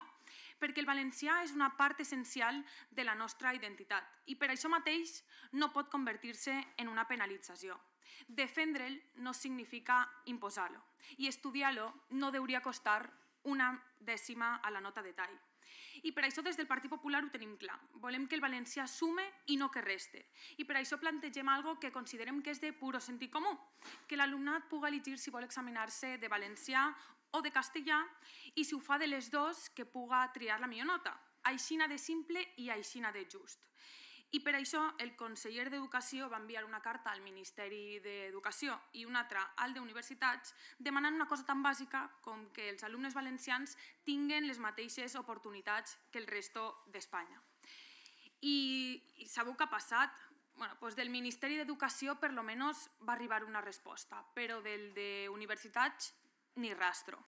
Así lo ha defendido la diputada del GPP en la Comisión de Educación y Cultura donde el Partido Popular ha llevado una proposición no de ley para exigir al Gobierno que el alumnado valenciano pueda examinarse en la fase obligatoria de la PAU de una única lengua (Valenciano o Castellano) o, en caso de examinarse de ambas, que solo compute la calificación más alta obtenida entre las pruebas.